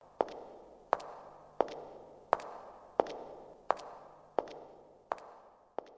Звуки каблуков
Звук элегантной прогулки по коридору на каблуках